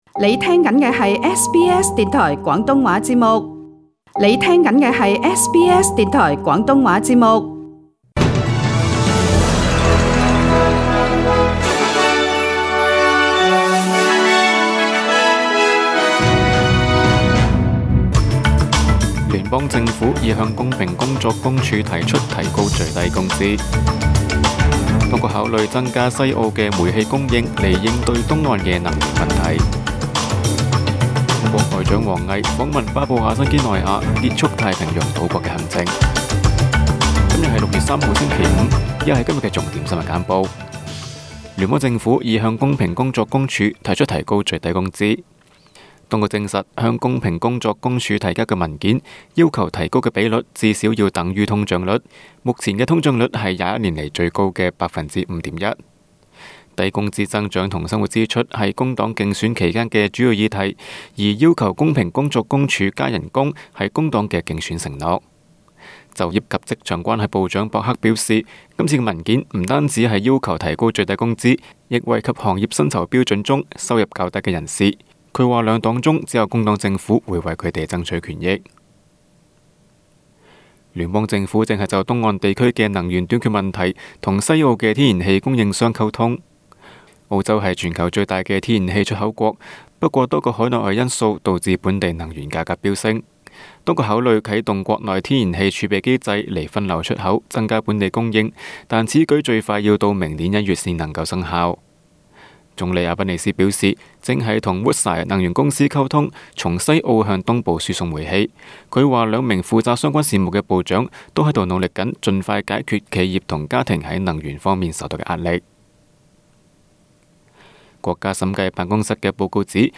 SBS 新闻简报（6月3日）
SBS 廣東話節目新聞簡報 Source: SBS Cantonese